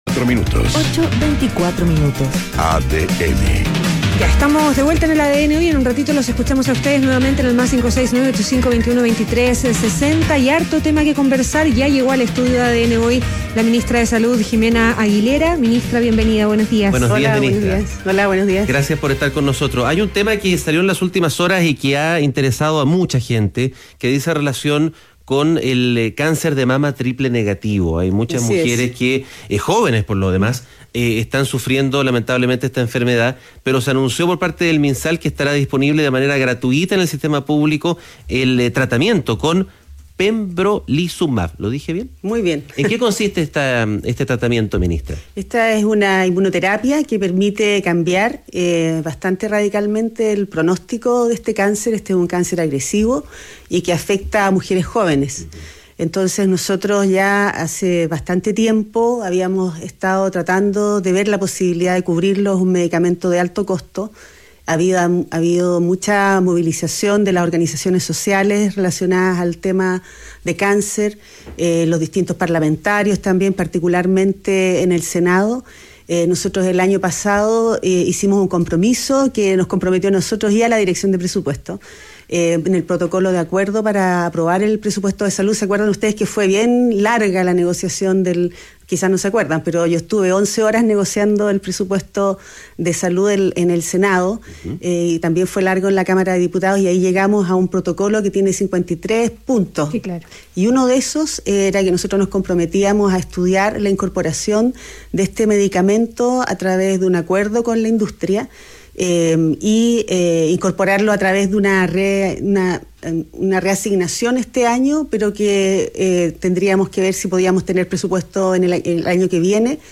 Entrevista a Ximena Aguilera, ministra de Salud - ADN Hoy